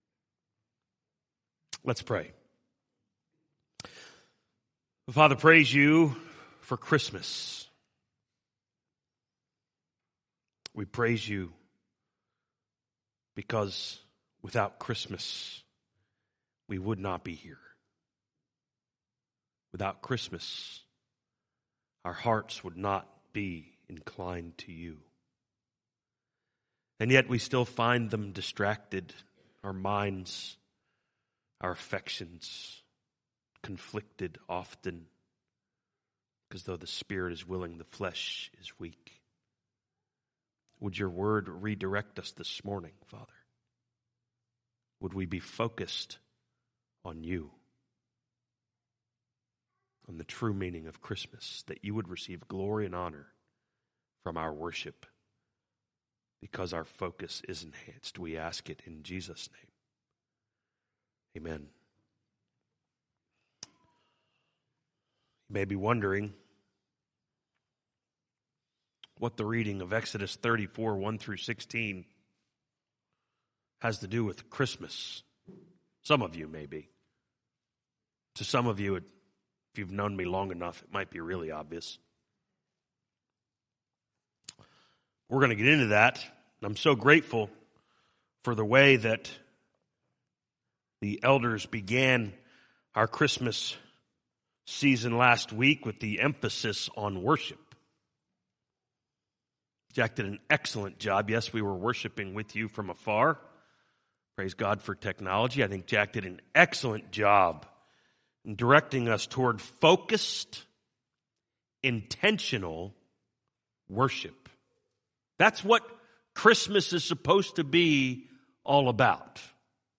From Series: "2023 Sermons"